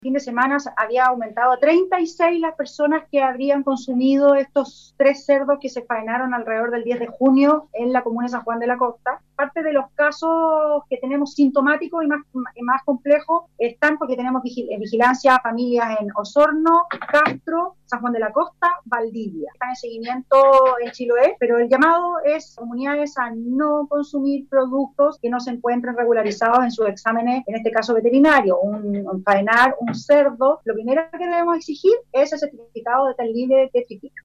En tanto, la seremi de salud Scarlett Molt, señaló: